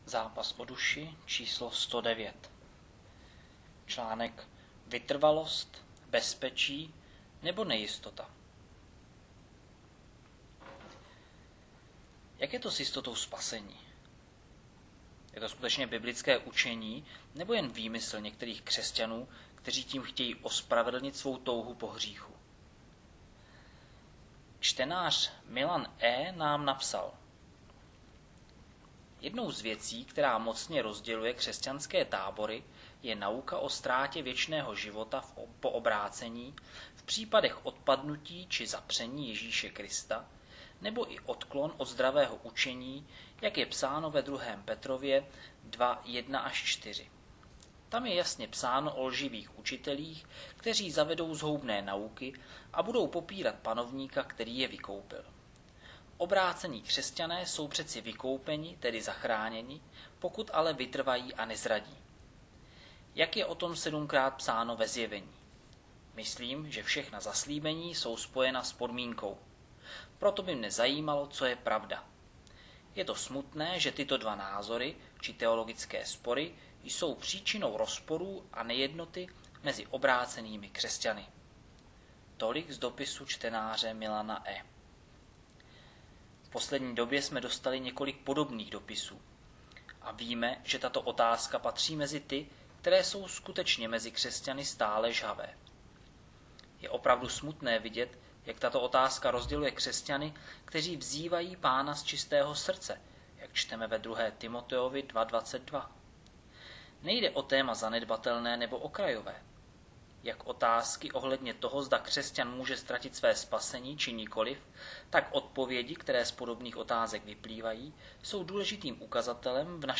Nahrávka článku o jistotě spasení ze Zápasu o duši č. 109.